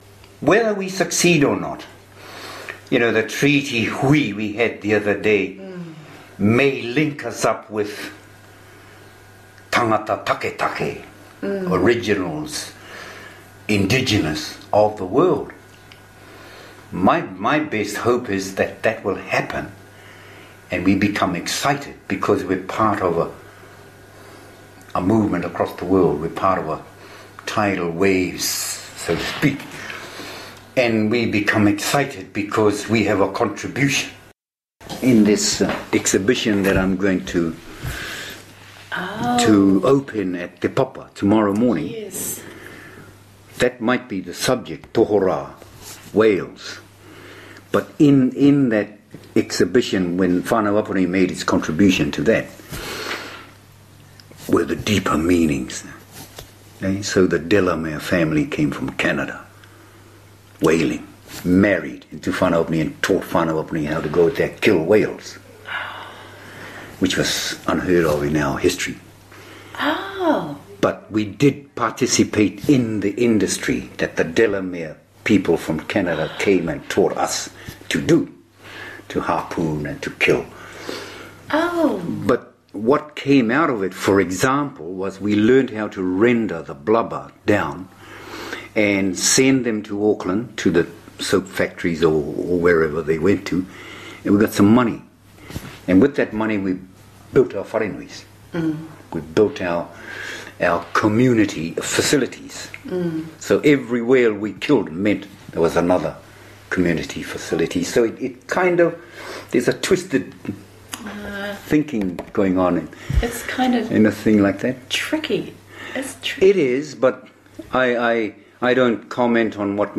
Click here to listen to conversations in English on Globalization with Maori and Gaelic speakers involved with indigenous language revitalization efforts.